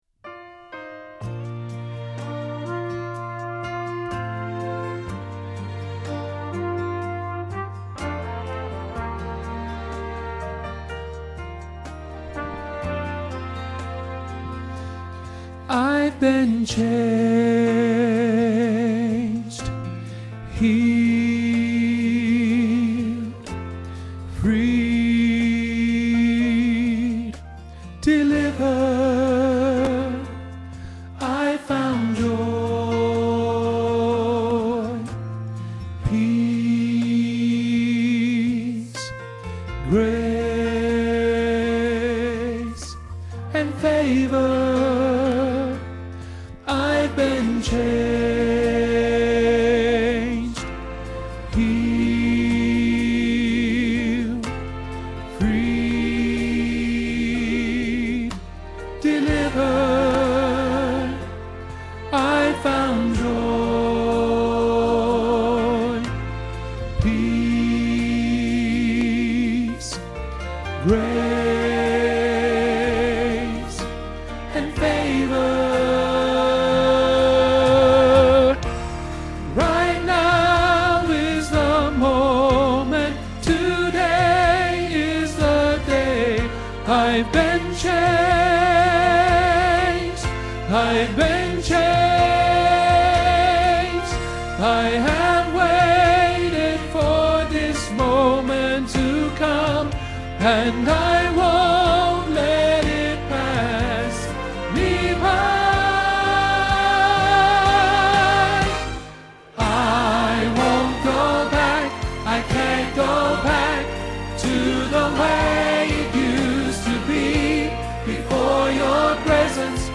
I Won’t Go Back – Tenor – Hilltop Choir
I-Wont-Go-Back-tenor.mp3